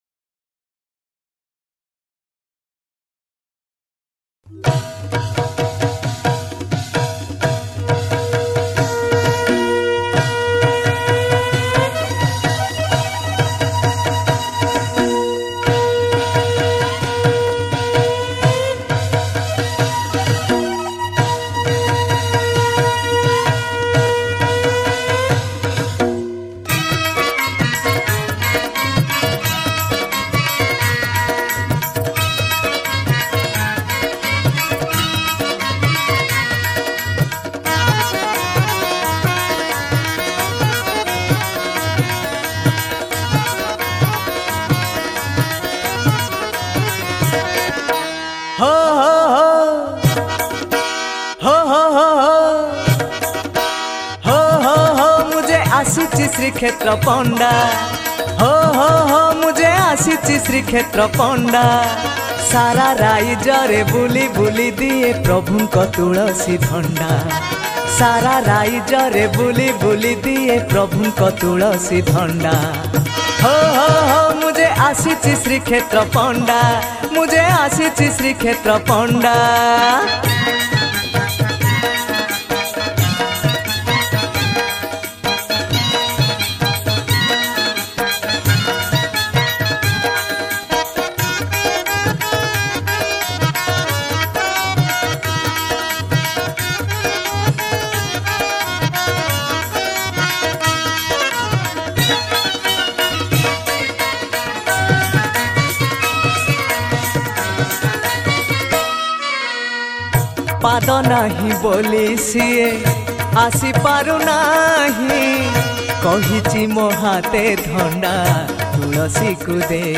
Odia Bhajan Songs